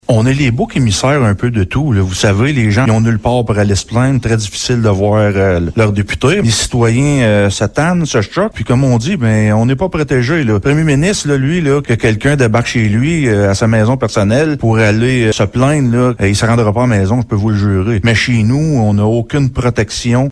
Selon le maire de Gracefield, Mathieu Caron, les élus municipaux encaissent souvent les conséquences de décisions qui sont prises par les élus d’autres paliers gouvernementaux :